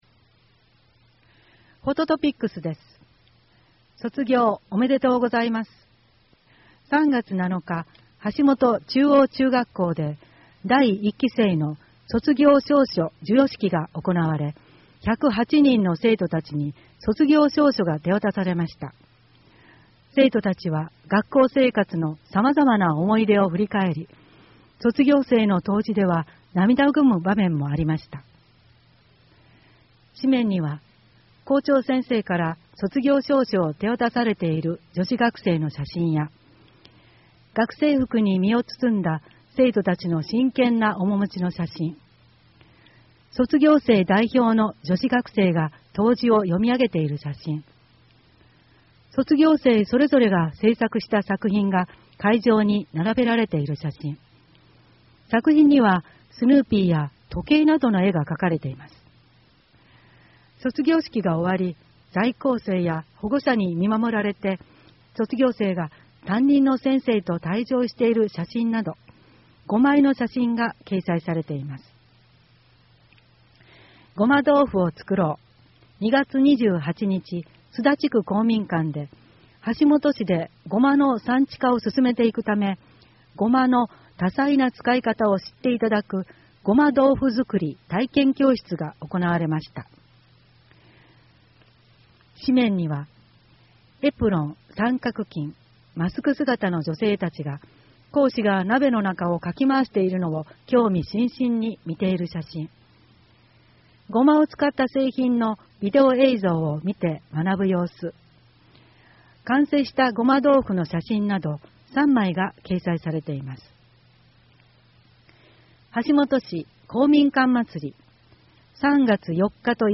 WEB版　声の広報 2017年4月号